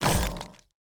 Minecraft Version Minecraft Version snapshot Latest Release | Latest Snapshot snapshot / assets / minecraft / sounds / block / dried_ghast / hit2.ogg Compare With Compare With Latest Release | Latest Snapshot